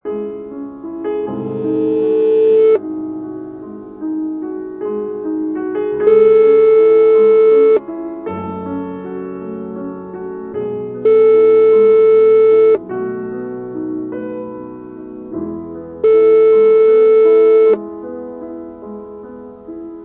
Adagio sostenuto